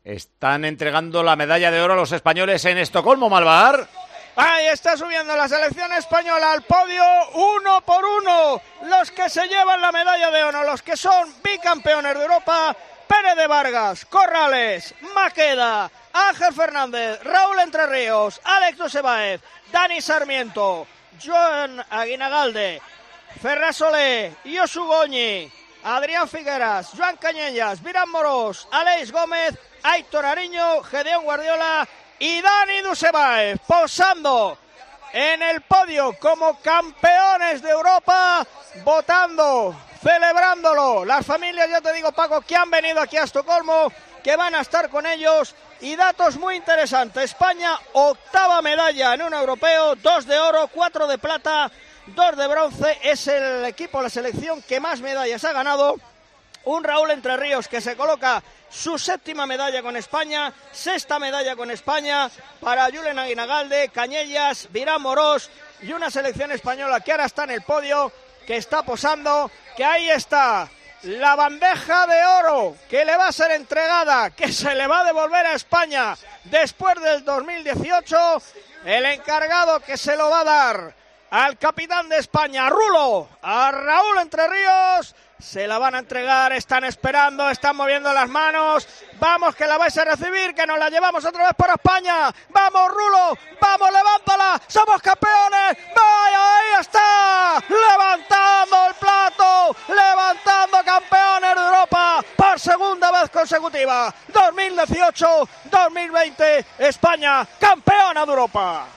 Así vivimos en Tiempo de Juego la entrega del entorchado europeo a 'Los Hispanos', narrado por